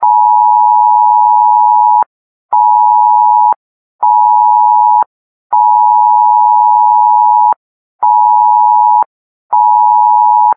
cmas_alert.ogg